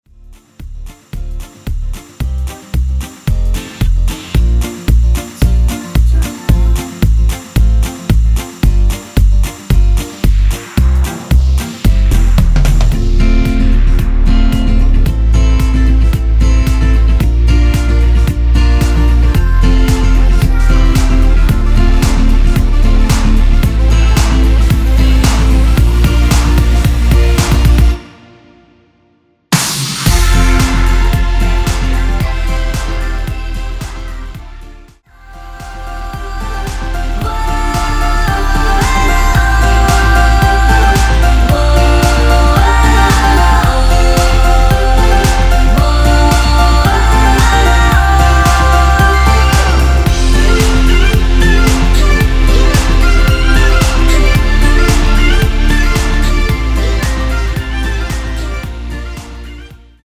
원키에서(+5)올린 코러스 포함된 MR입니다.
앞부분30초, 뒷부분30초씩 편집해서 올려 드리고 있습니다.